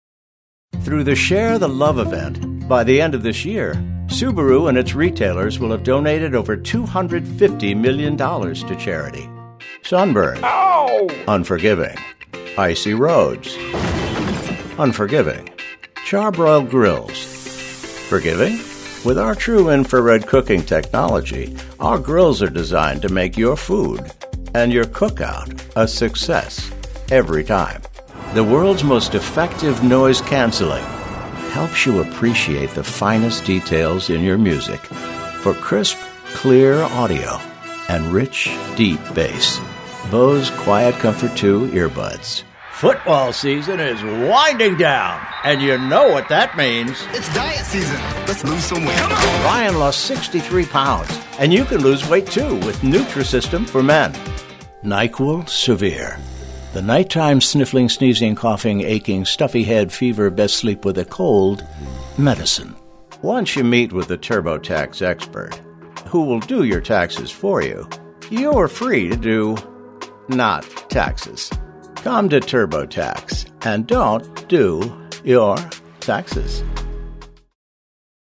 Commercial Demo
English (North American)